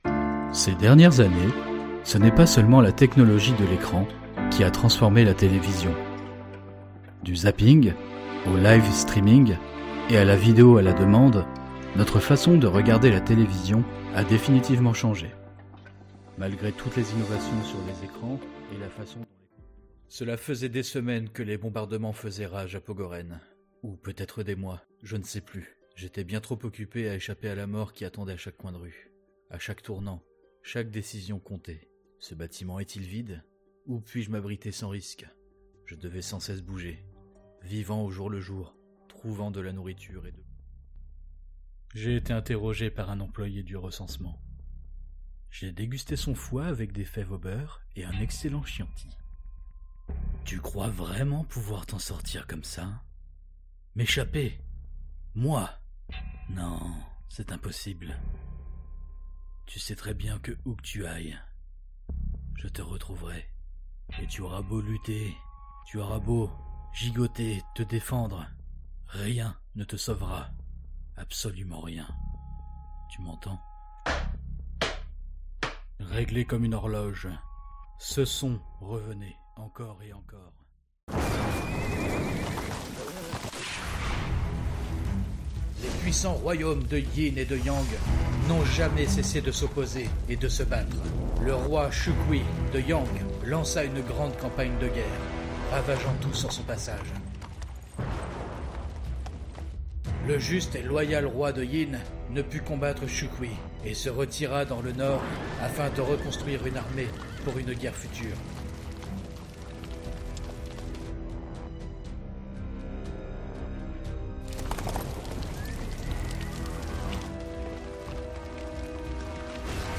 Voix off
25 - 50 ans - Baryton